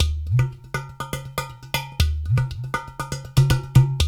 120 -UDU 0ER.wav